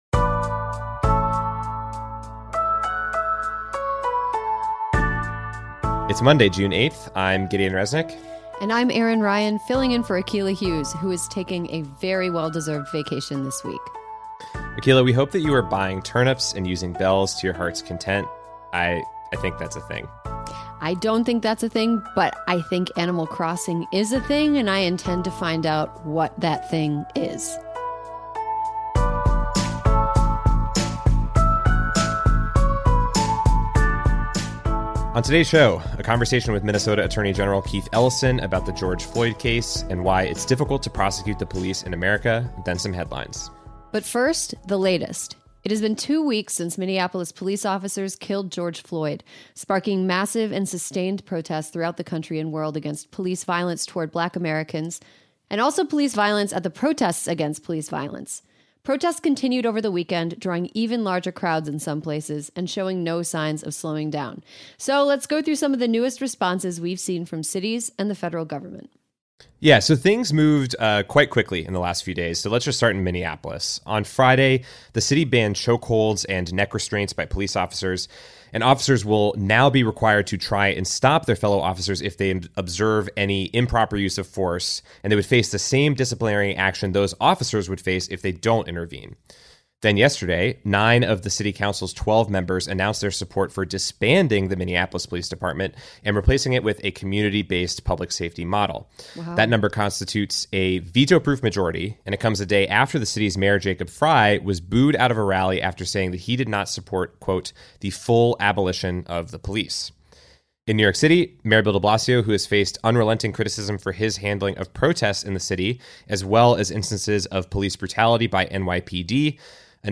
We interview Minnesota Attorney General Keith Ellison, who recently took the lead in the prosecuting cases related to George Floyd’s killing by police. We ask him why it's so difficult to prosecute officers, and what he makes of moves to disband police departments.